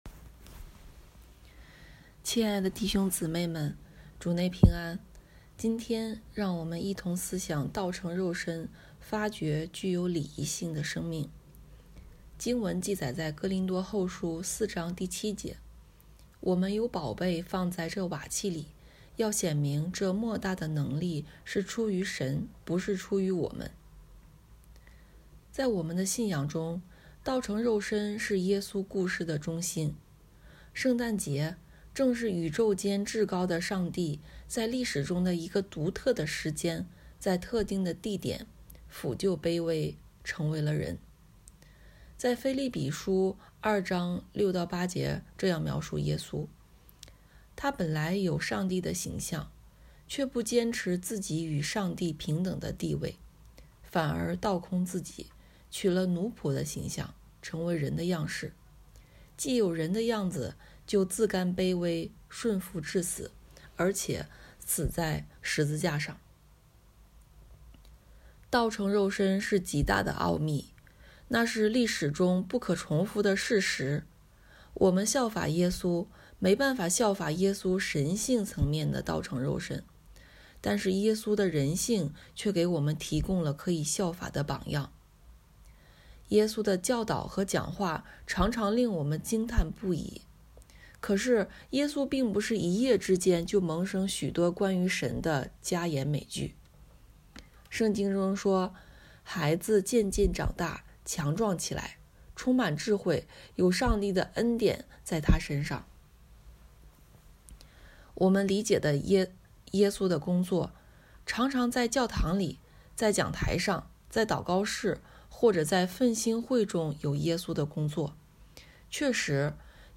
题目：效法基督系列之六——道成肉身，发掘具有礼仪性的生命 证道